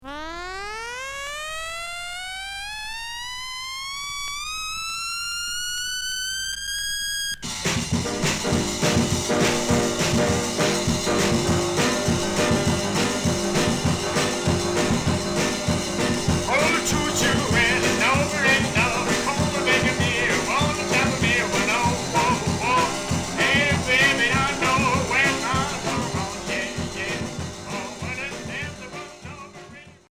R'n'b garage Unique 45t retour à l'accueil